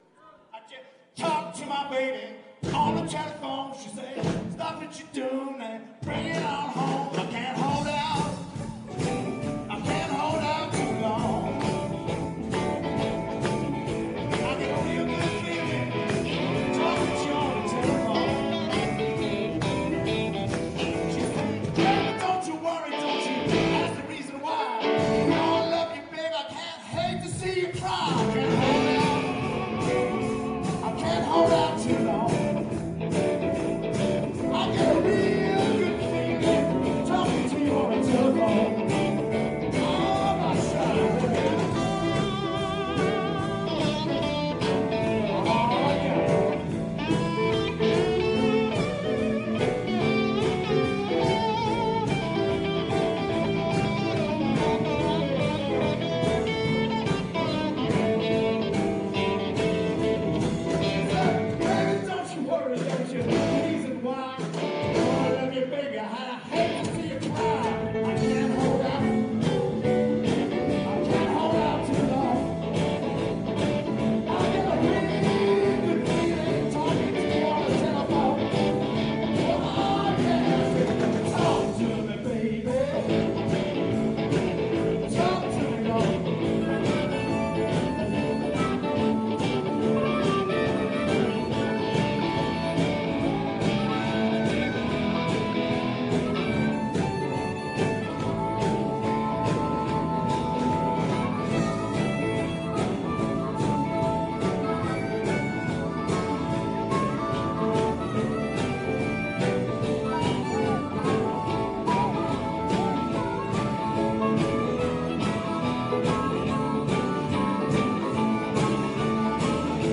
(Marcq en Baroeul 2003)